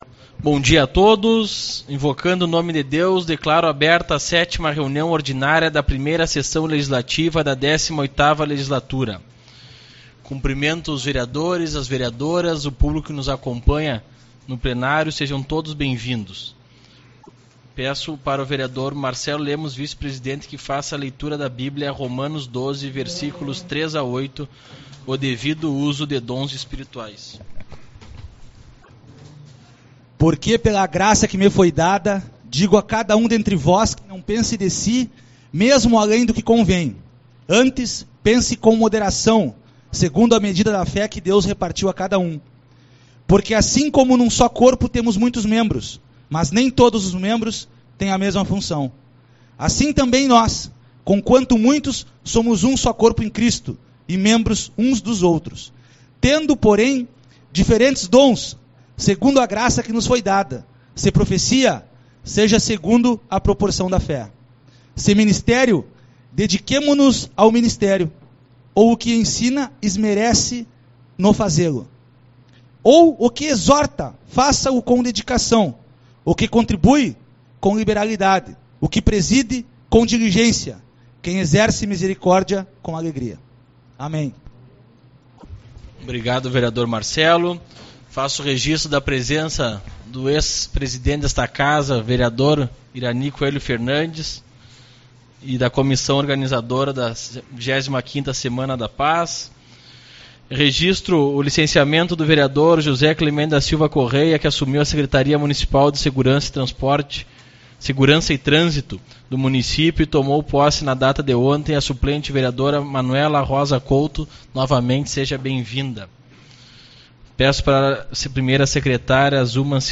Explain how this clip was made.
25/02 - Reunião Ordinária